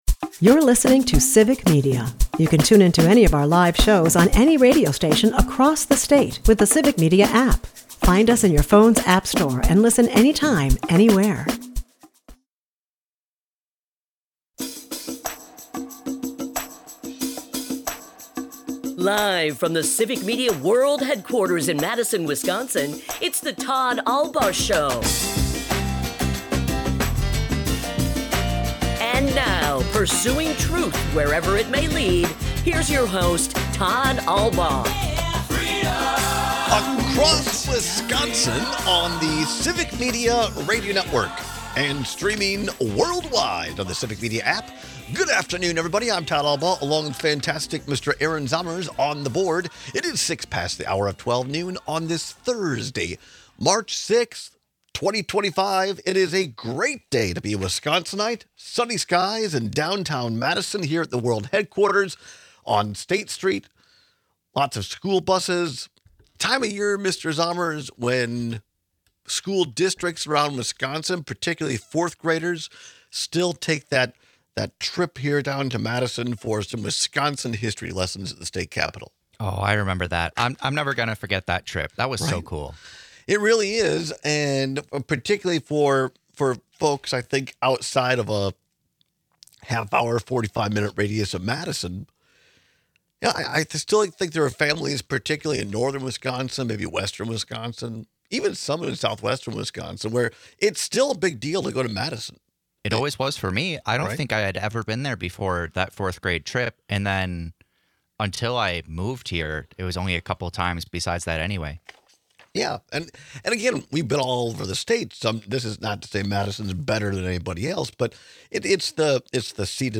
We take your calls with thoughts on Schimel’s ageist rhetoric.